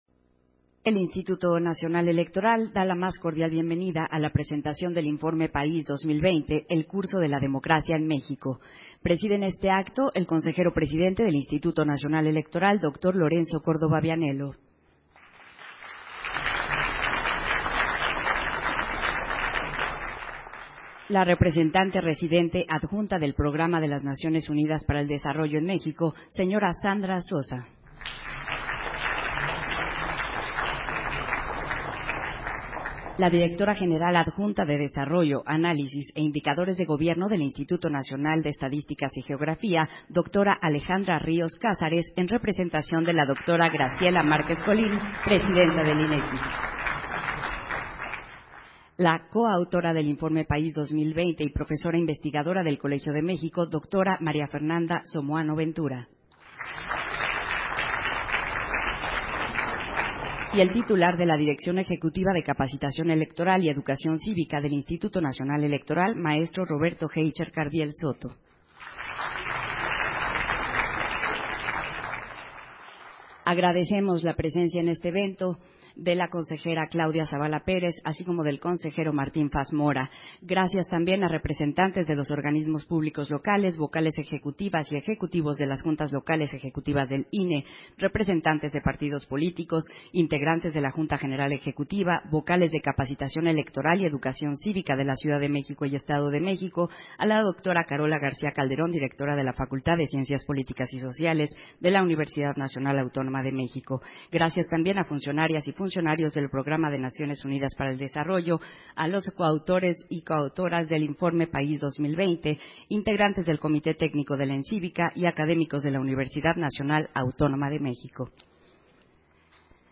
141022_AUDIO_INAUGURACIÓN_PRESENTACIÓN-INFORME-PAÍS-2020